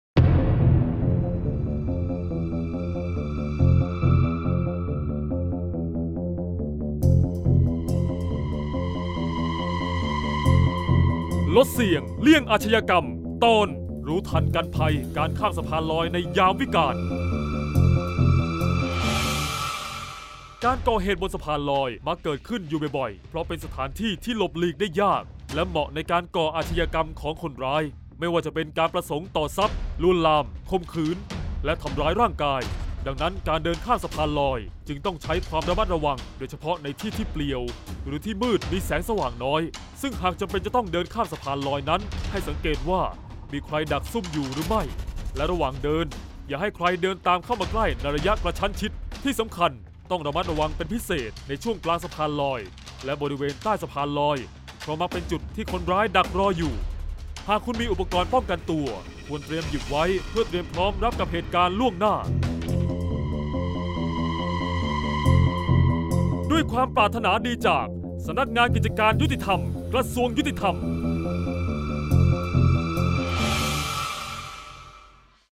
เสียงบรรยาย ลดเสี่ยงเลี่ยงอาชญากรรม 32-ระวังภัยสะพานลอย